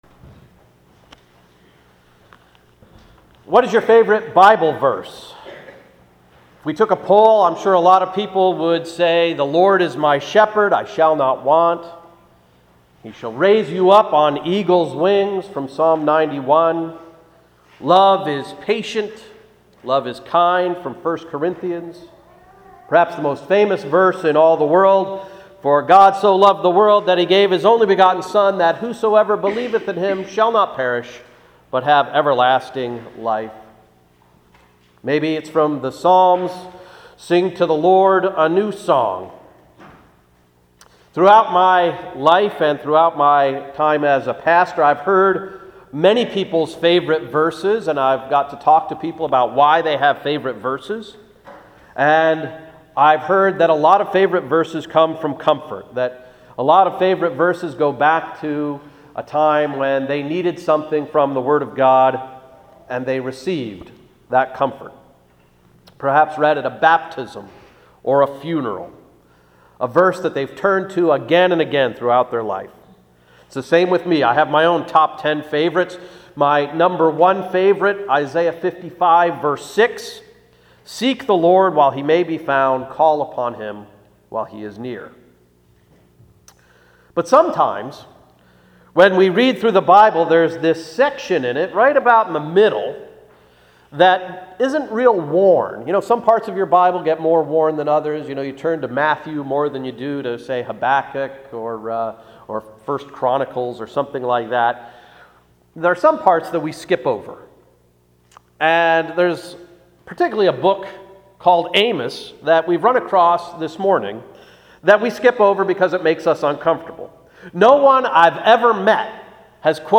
Sermon of September 22, 2013–“Skipping the Prophets”